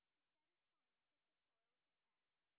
sp20_white_snr0.wav